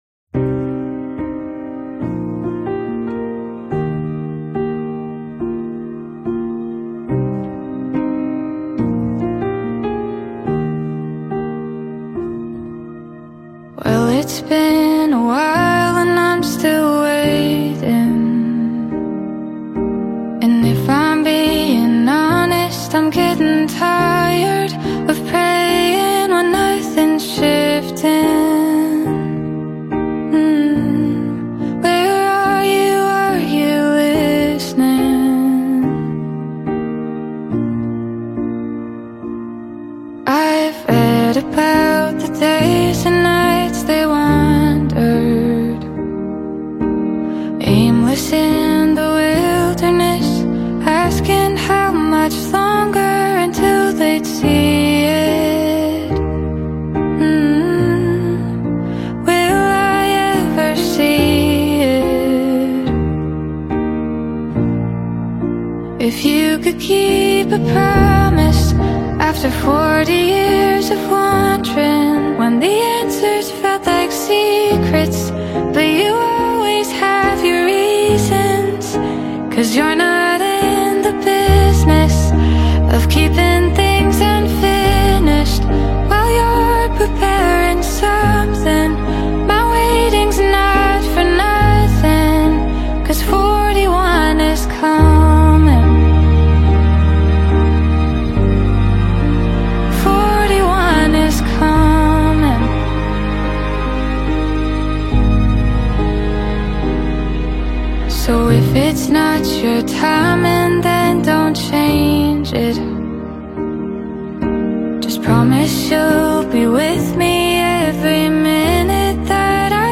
deeply emotional and reflective gospel song
with a tone of humility and sincerity
worship piece